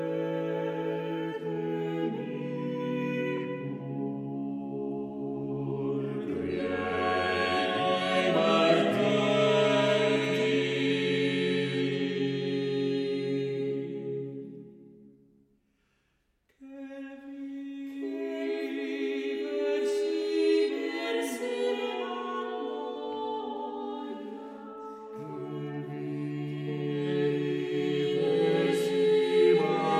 Terzo libro di madrigali, A cinque voci
Mêlant à la perfection sa maîtrise interprétative et une fraîcheur vocale innovante, La Compagnia del Madrigale nous invite à un voyage sonore fascinant à travers ces madrigaux sans accompagnement instrumental.